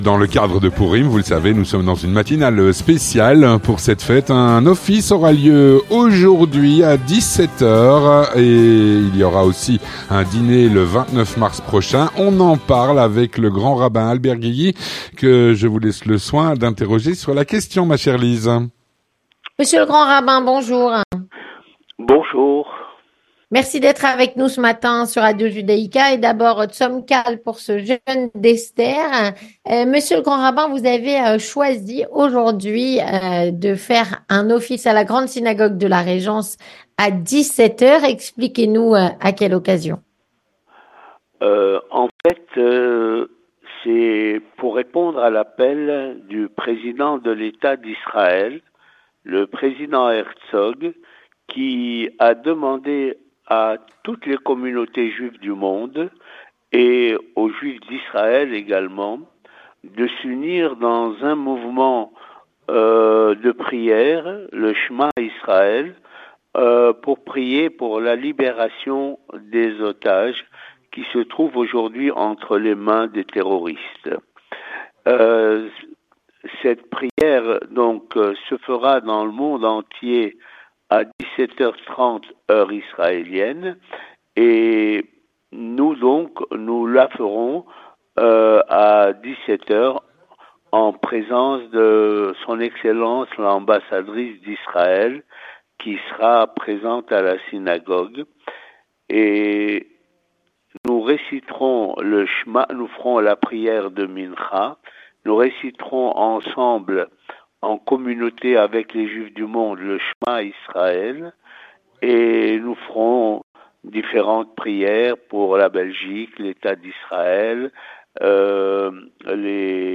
Avec Albert Guigui, Grand Rabbin de Bruxelles.